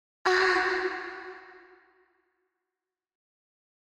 Звук влюбленной девушки, вздыхающей от нежности